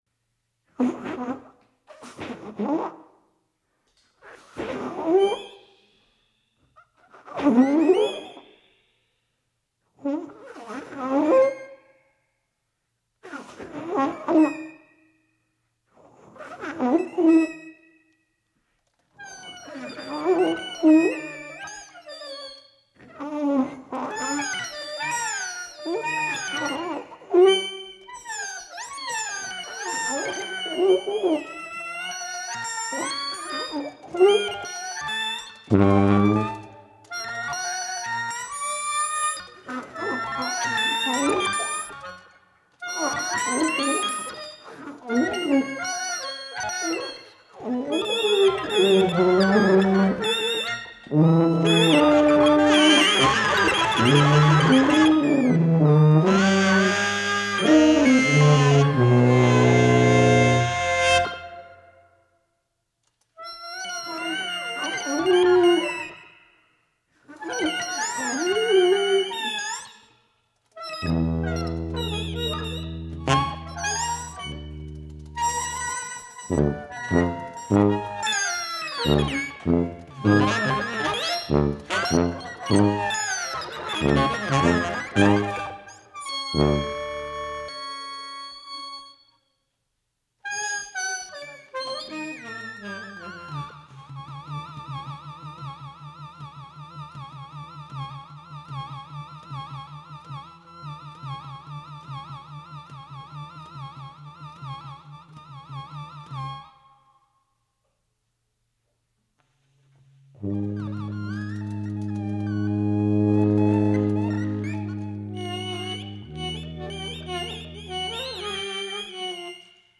Recorded live at ABC NoRio, Lower East Side, Manhattan
tuba
alto saxophone, electronics
Stereo (722 / Pro Tools)